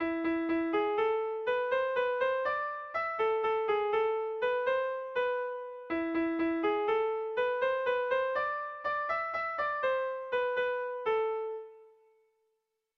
Irrizkoa
Kopla handia
ABD